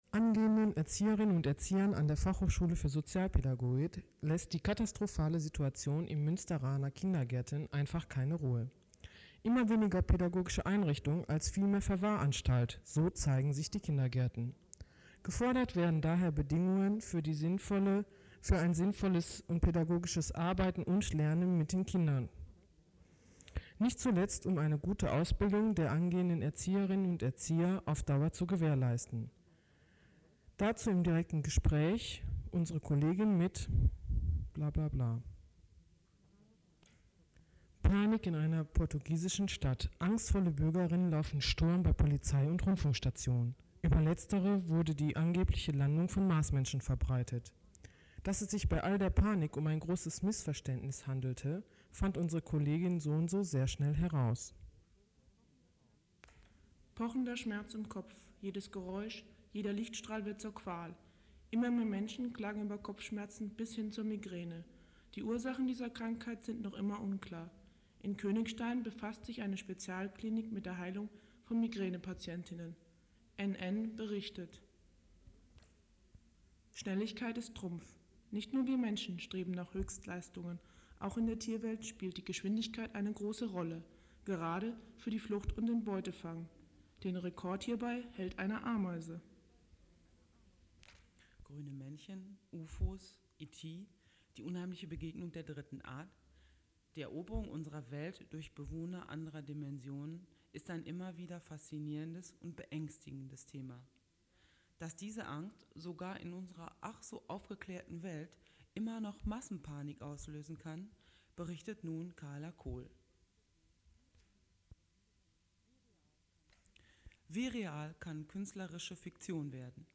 Mit diesem Slogan kündigten zwischen Januar 1992 und Mai 2004 eine Gruppe von (in ihren Hochphasen) bis zu acht Studentinnen ihre Innovation an: Im Januar 1992 hatten sie sich aufgemacht, die Einrichtung des offenen Kanals im Lokalradio ‚Antenne Münster‘ zu nutzen, um Frauen und ihren Sichtweisen mehr Gehör zu verschaffen.